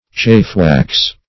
Chafewax \Chafe"wax`\, or Chaffwax \Chaff"wax`\, n. (Eng. Law)